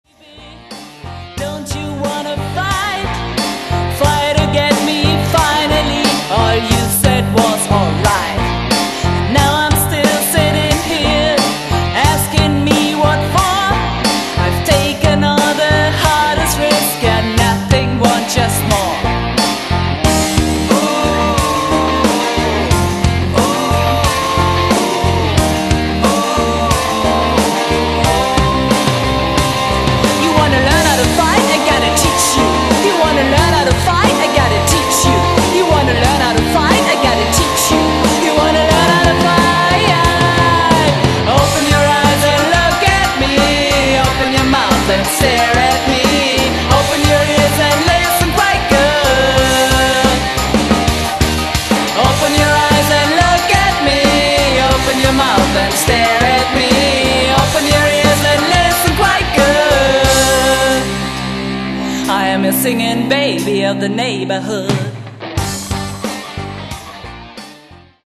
Gesang
Drums
Bass
Keys
Gitarre